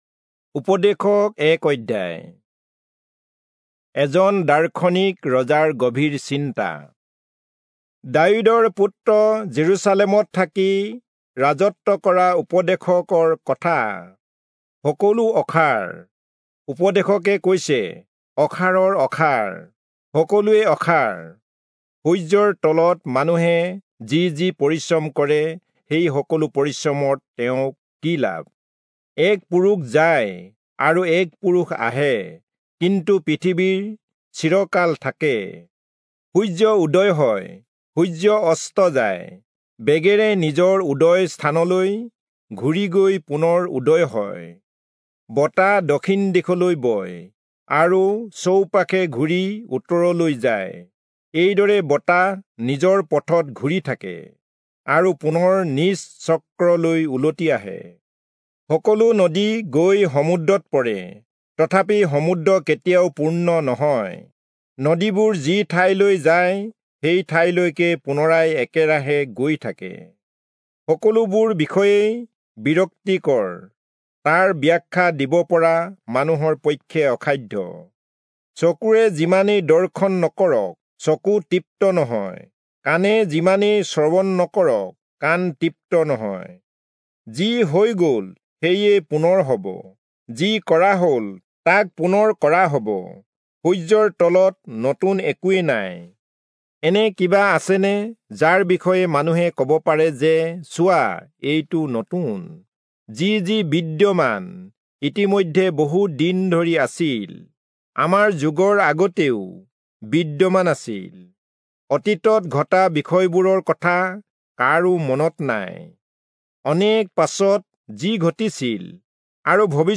Assamese Audio Bible - Ecclesiastes 5 in Guv bible version